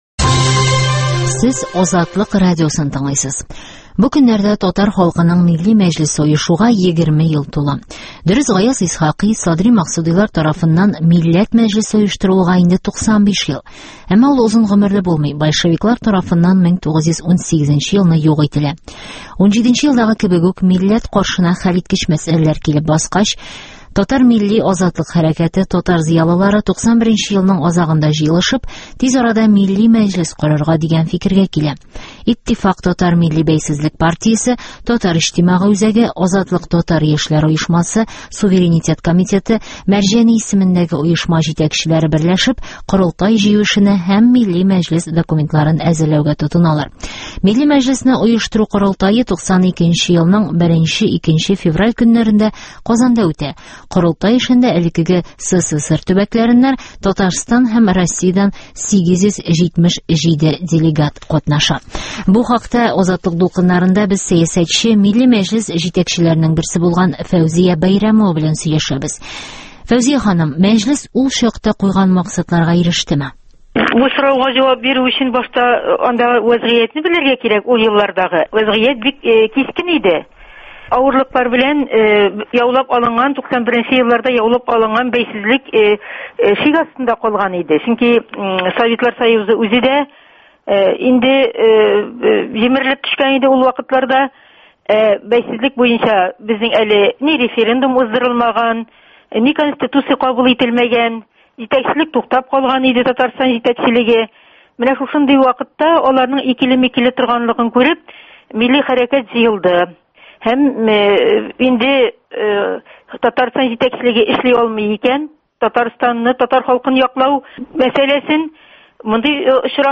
Фәүзия Бәйрәмова белән әңгәмә